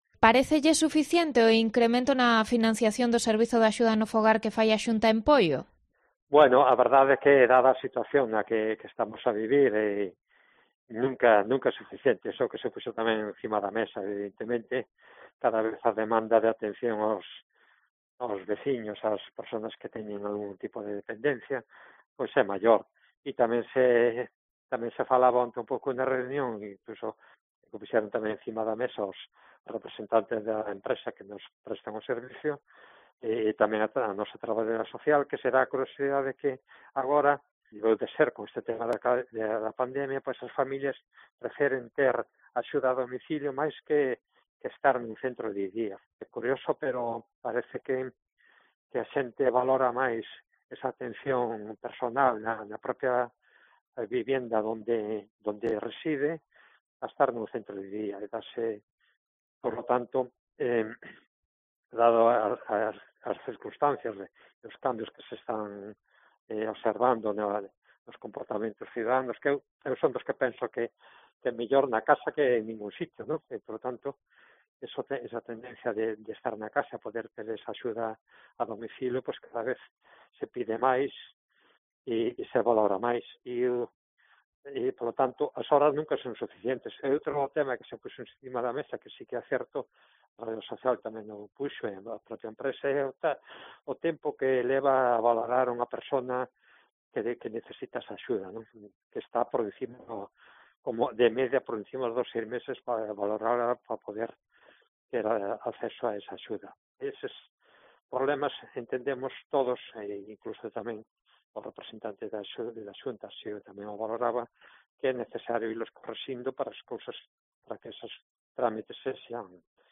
Entrevista al alcalde de Poio, Luciano Sobral, sobre el Servicio de Ayuda en el Hogar